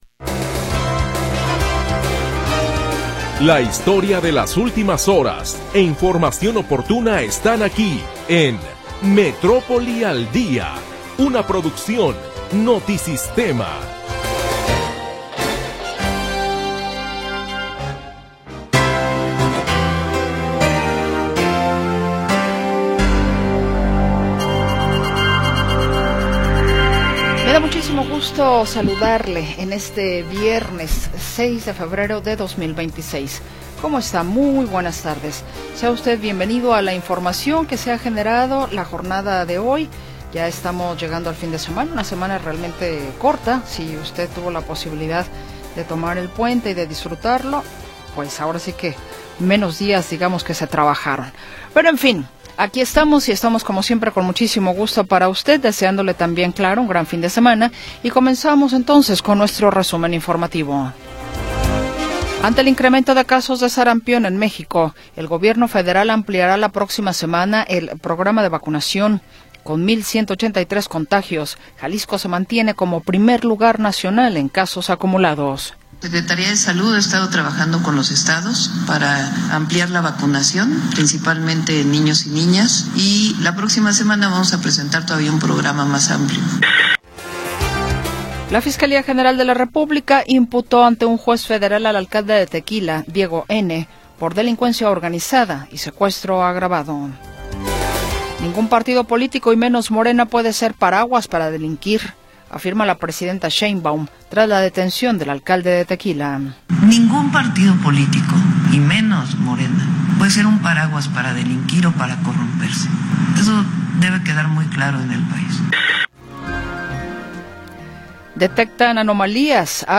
Primera hora del programa transmitido el 6 de Febrero de 2026.